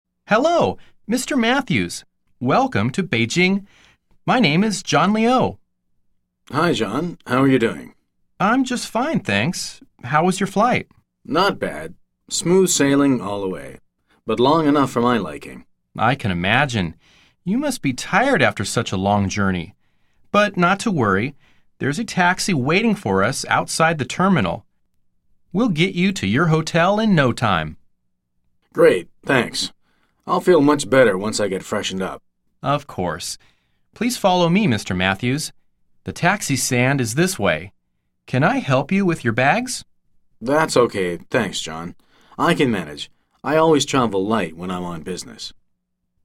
實戰對話 →